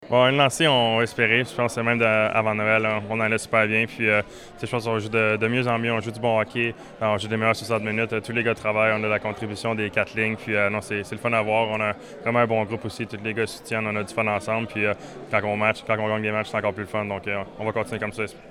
Présent comme co-ambassadeur lors du lancement de la campagne de financement d’Entraide Bécancour, mardi, Samuel Montembeault, a mentionné que l’équipe peut continuer sur cette lancée.